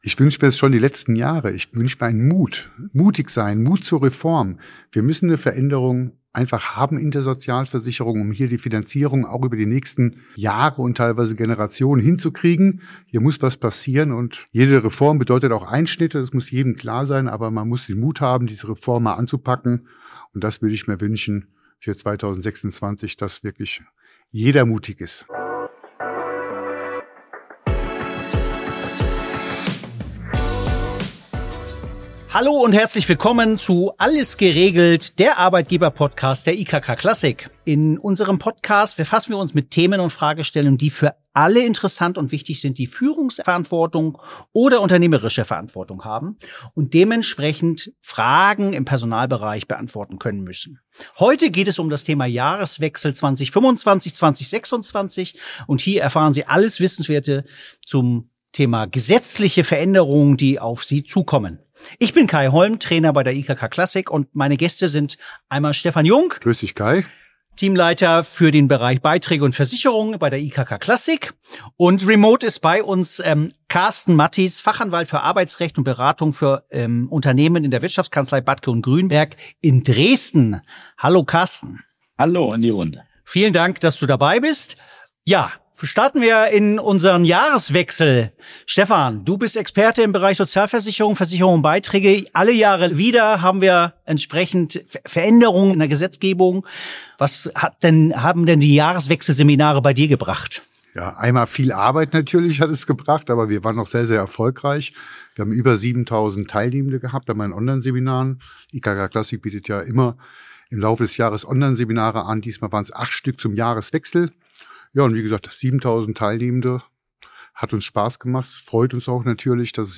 ins Studio geholt, um über die wichtigsten Änderungen zum Jahreswechsel zu informieren.